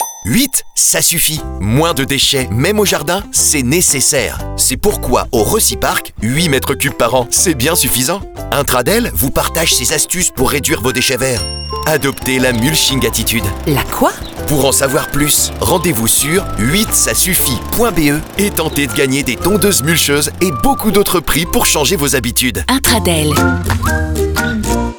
DES PUBS AUDIOS
Plus qu’un spot avec une bande sonore ordinaire, nous créons un univers sonore unique et distinct pour chaque spot avec des effets, des bruitages immersifs et une musique adaptée à votre production.